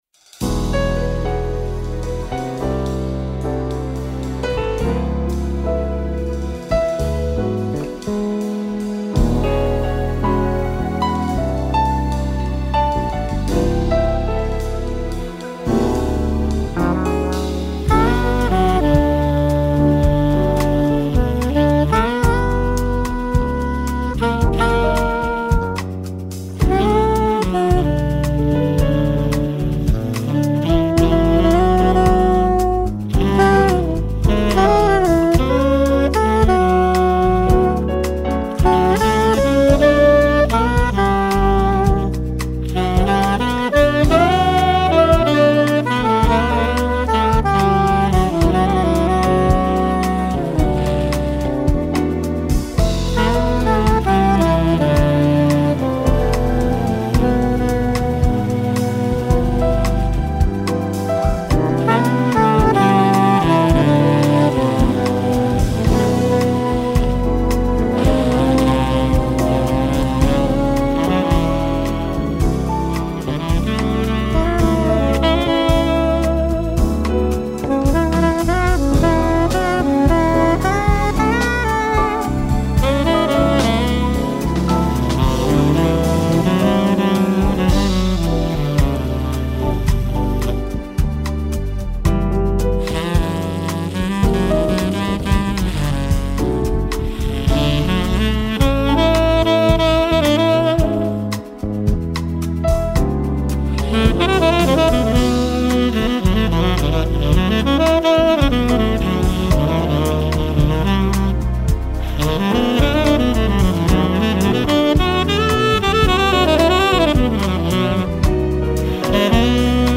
1171   04:12:00   Faixa:     Jazz